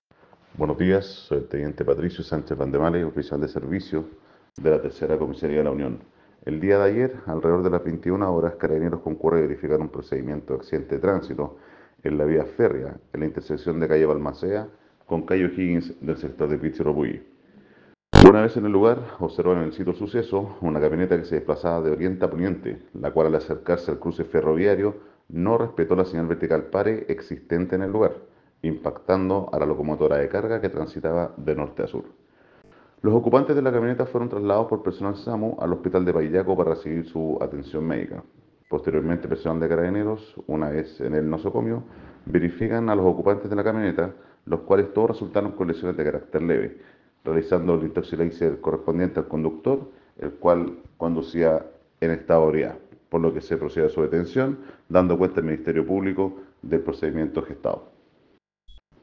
Parte Policial .